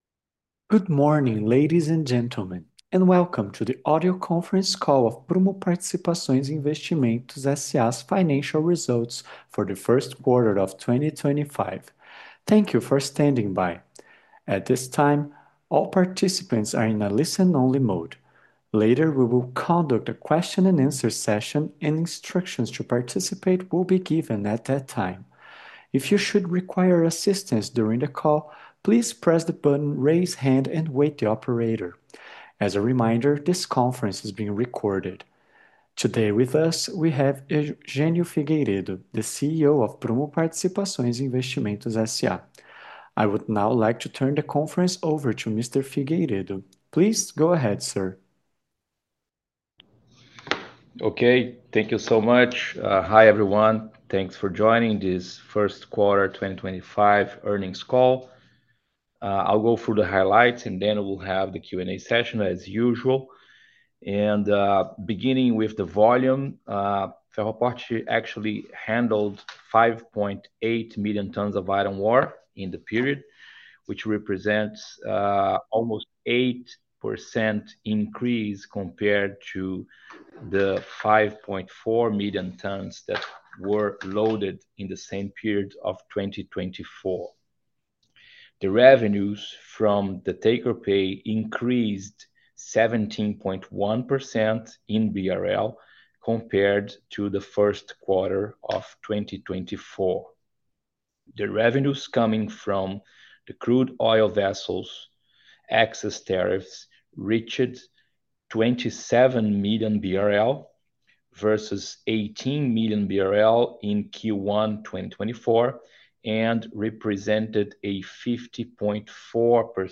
Conference-call-PPAR_1Q25.mp3.mp3